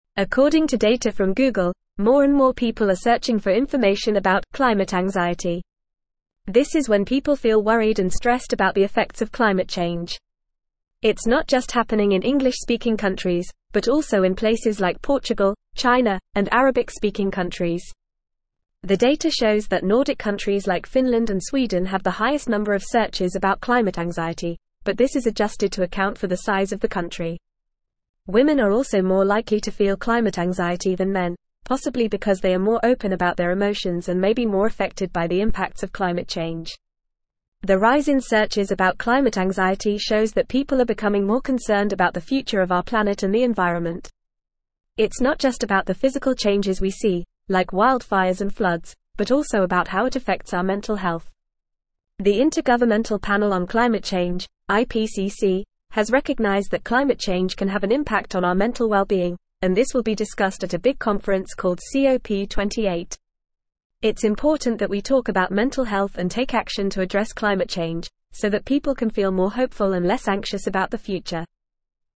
Fast
English-Newsroom-Upper-Intermediate-FAST-Reading-Rising-Search-Queries-Show-Womens-Climate-Anxiety-Increase.mp3